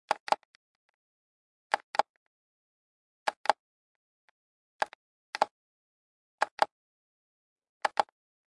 随机" 按键式塑料闹钟
描述：按钮按塑料闹钟.flac
Tag: 塑料 闹钟 时钟 按键 按下